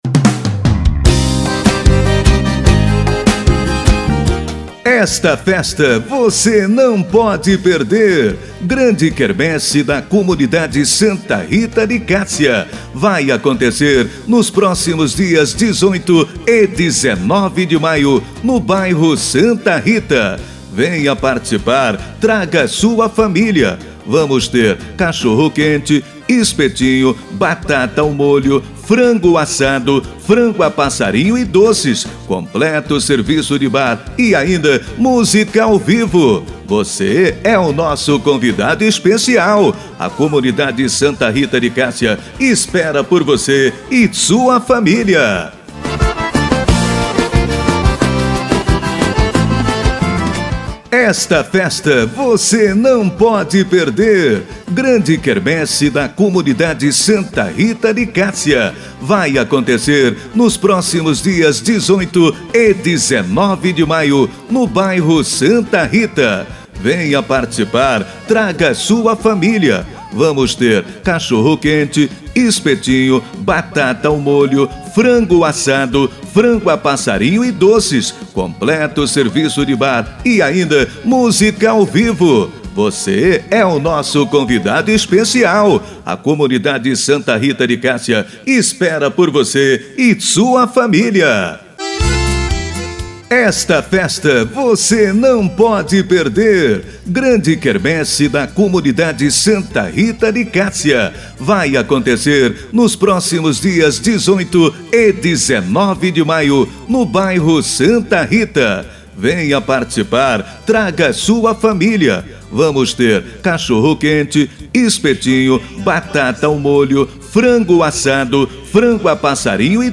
Propaganda de Som para Quermesse Santa Rita 2018